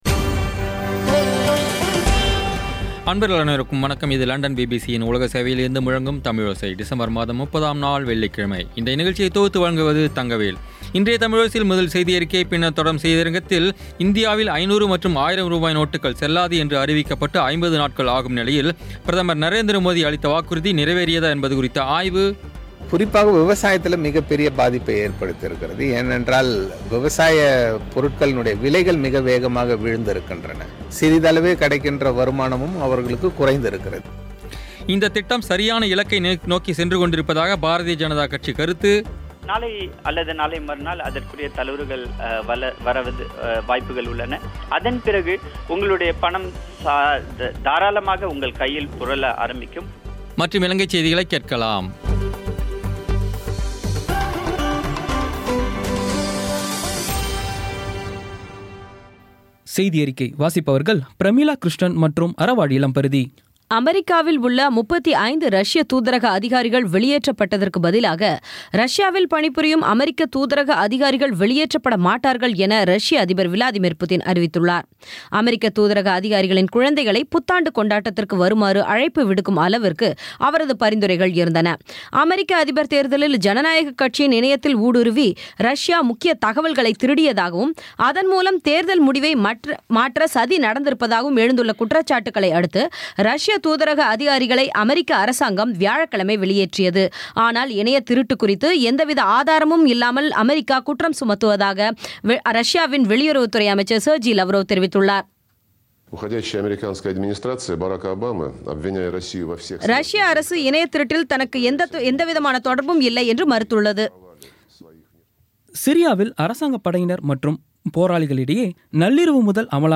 இன்றைய தமிழோசையில், முதலில் செய்தியறிக்கை, பின்னர் தொடரும் செய்தியரங்கத்தில், இந்தியாவில் 500 மற்றும் 1000 ரூபாய் நோட்டுக்கள் செல்லாது என்று அறிவிக்கபட்டு 50 நாட்கள் ஆகும் நிலையில், பிரதமர் நரேந்திர மோதி அளித்த வாக்குறுதி நிறைவேறியதா என்பது குறித்த ஆய்வு இந்தத் திட்டம் சரியான இலக்கை நோக்கிச் சென்று கொண்டிருப்பதாக பாரதீய ஜனதா கருத்து மற்றும் இலங்கைச் செய்திகளைக் கேட்கலாம்.